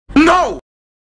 دانلود آهنگ نه خارجی از افکت صوتی انسان و موجودات زنده
جلوه های صوتی
دانلود صدای نه خارجی از ساعد نیوز با لینک مستقیم و کیفیت بالا